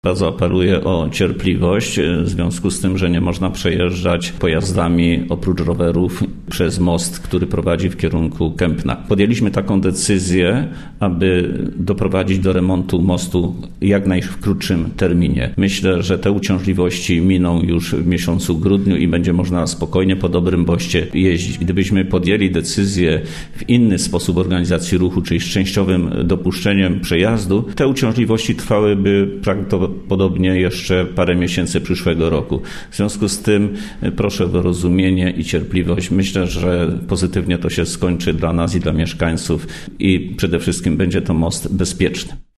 – mówił wicestarosta powiatu wieruszowskiego, Stefan Pietras.